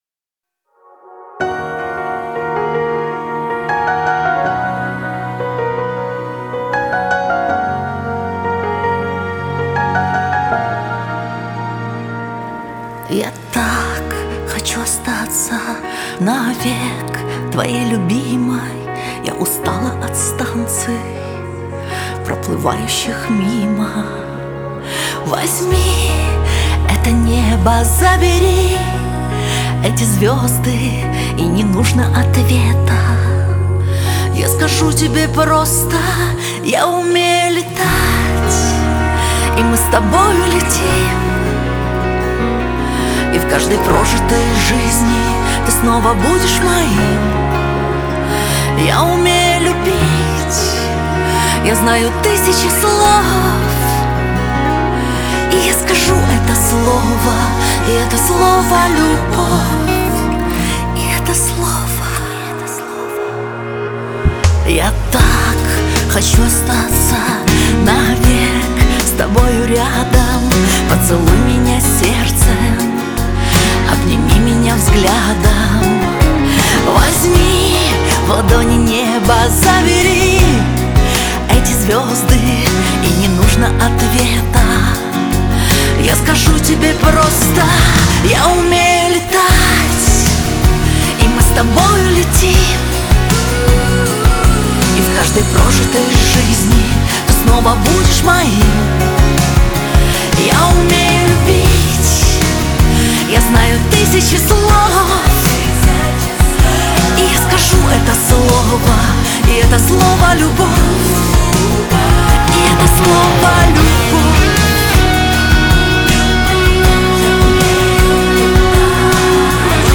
это яркий пример поп-баллады